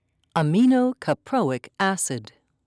(a-mee-noe-ka-proe'ik)